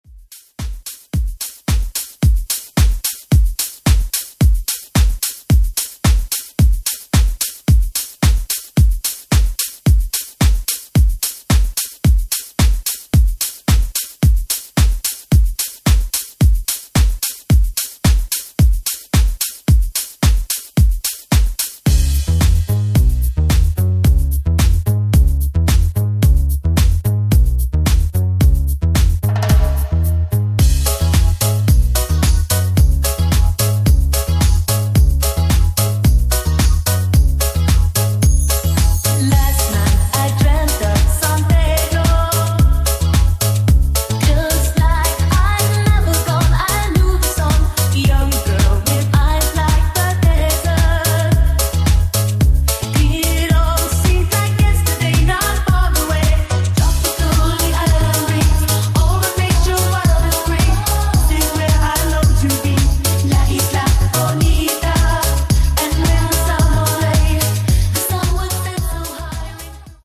NU-Disco Remix